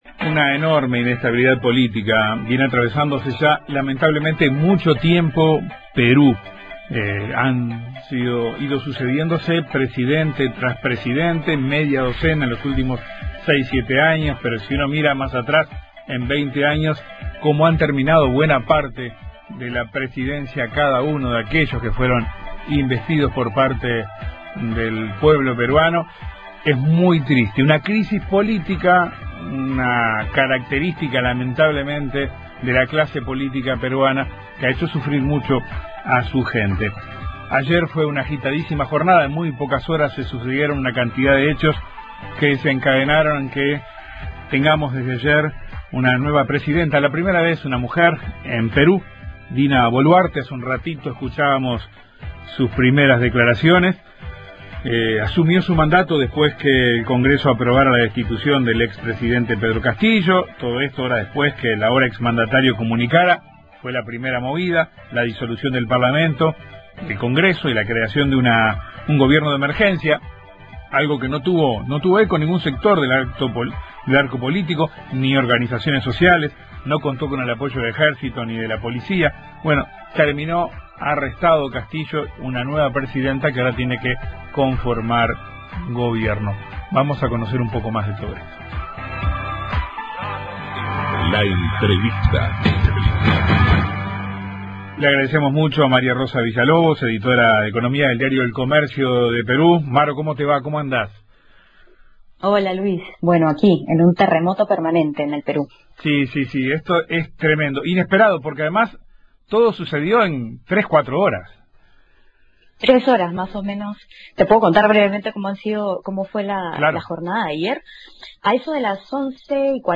La comunicadora narró en Informativo Uruguay la vertiginosa cadena de sucesos que en tres horas, ayer, terminaron con la destitución del presidente que pretendía disolver las cámaras y la asunción de Dina Boluarte, quien formó parte del gobierno de Castillo hasta fines del pasado año.